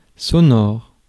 Ääntäminen
Ääntäminen France: IPA: /sɔ.nɔʁ/